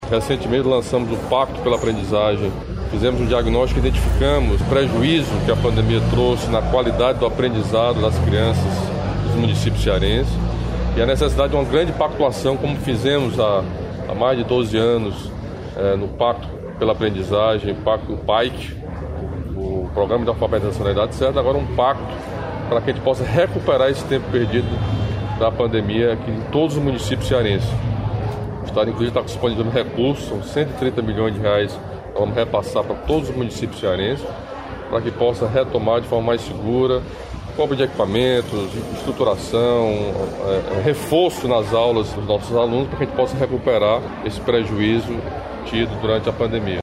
Ainda durante a inauguração da nova escola, o governador Camilo Santana falou sobre o pacto pela aprendizagem firmado com os municípios para recuperar as perdas provocadas pela pandemia da Covid-19.